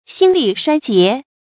注音：ㄒㄧㄣ ㄌㄧˋ ㄕㄨㄞ ㄐㄧㄝ ˊ
心力衰竭的讀法